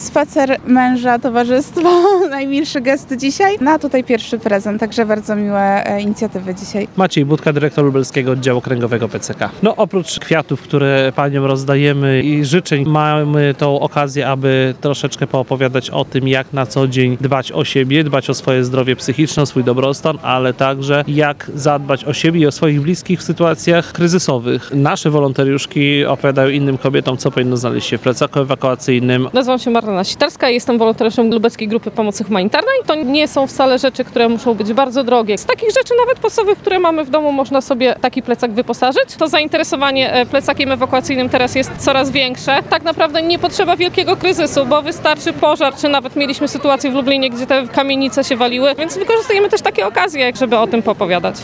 Dzień Kobiet z PCK został zorganizowany przy lubelskim ratuszu na Placu Łokietka.